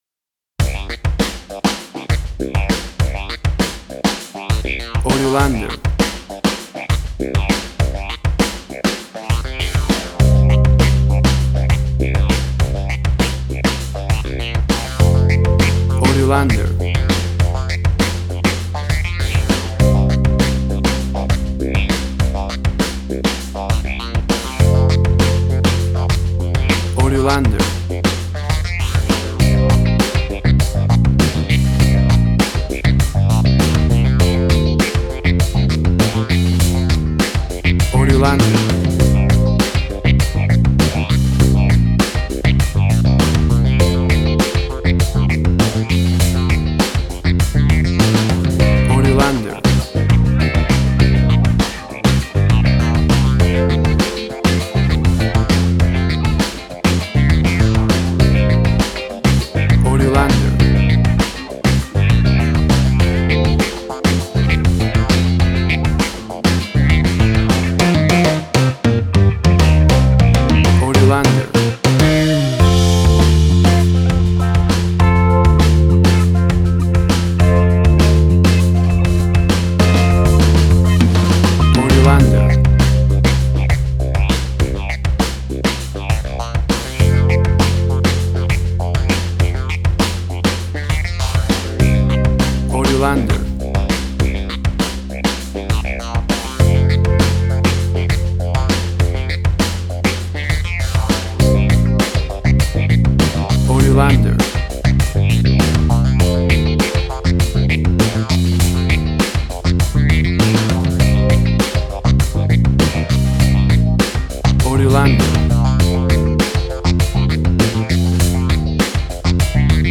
Tempo (BPM): 100